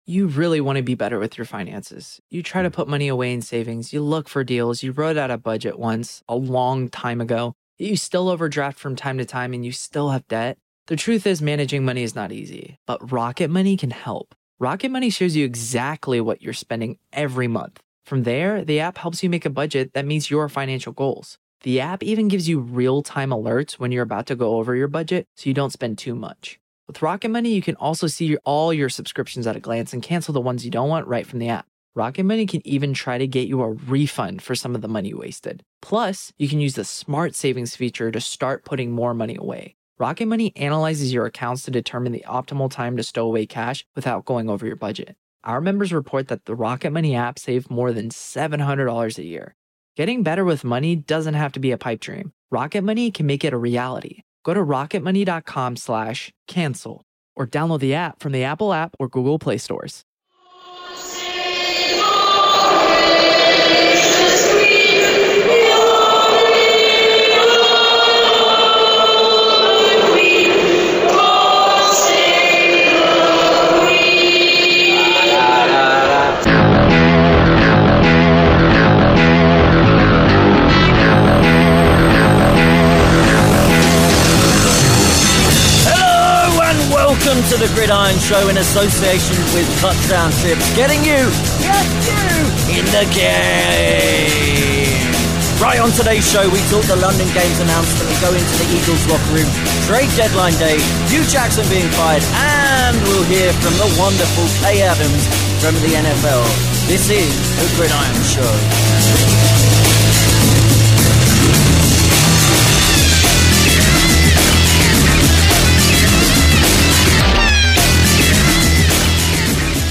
Plus hear our post game chats with Malcolm Jenkins, Avonte Maddox and Jake Elliot from the Eagles locker room AND Kay Adams from GMFB on her trip to London.